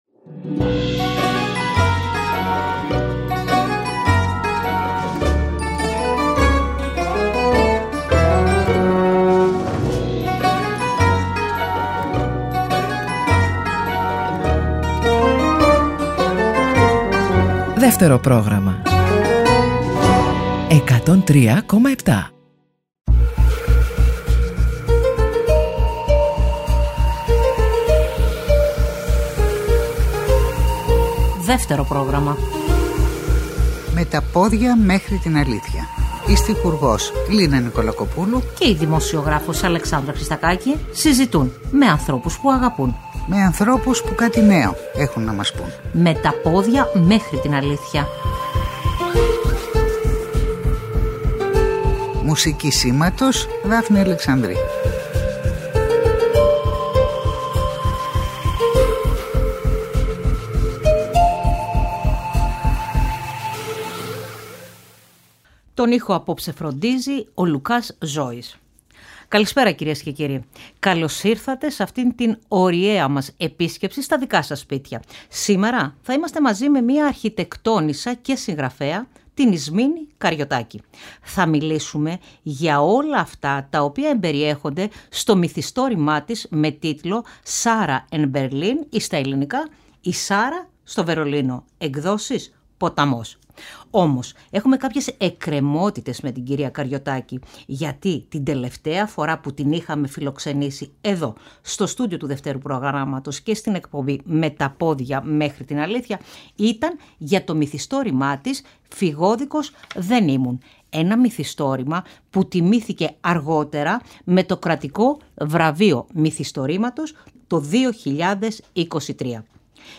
Συζήτησε με την στιχουργό Λίνα Νικολακοπούλου